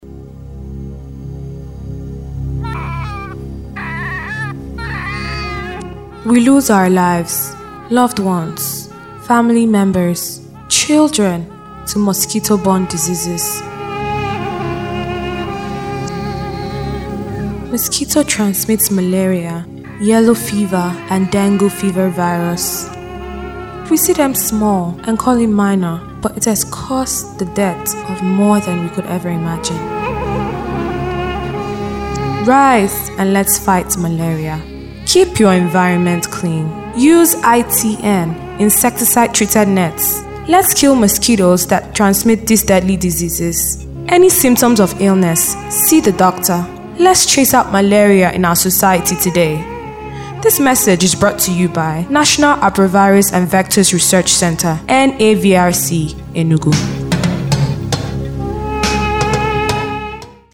NAVRC-Advert.mp3